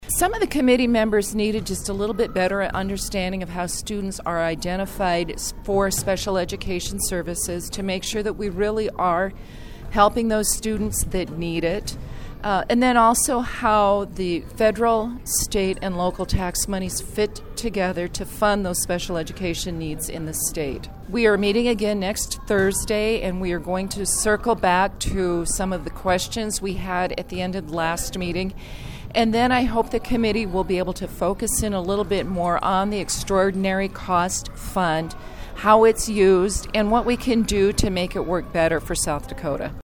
The group met for the first time last month and committee chair District 24 representative Mary Duvall of Pierre says the group will learn more how special education funding works in South Dakota.